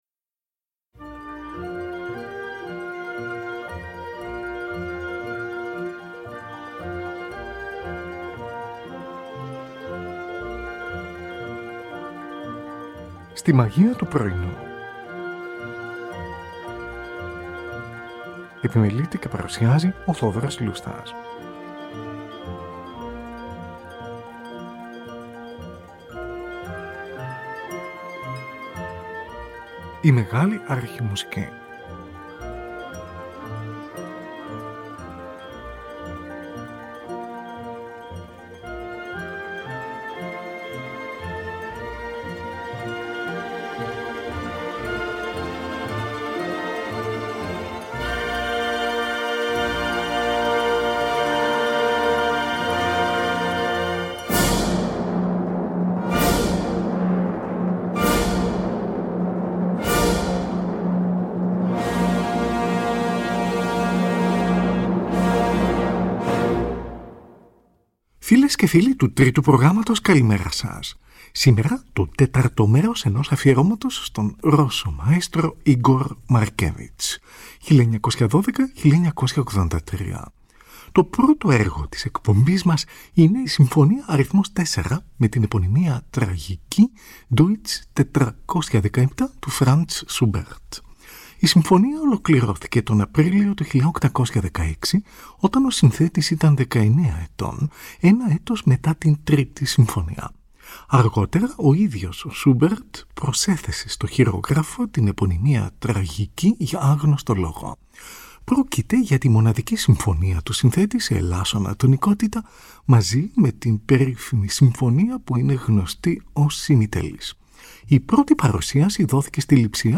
Συμφωνικό Ποίημα